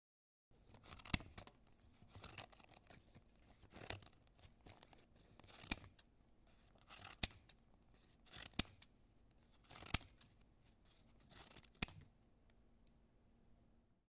دانلود آهنگ قیچی از افکت صوتی اشیاء
جلوه های صوتی
دانلود صدای قیچی از ساعد نیوز با لینک مستقیم و کیفیت بالا